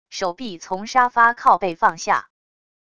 手臂从沙发靠背放下wav音频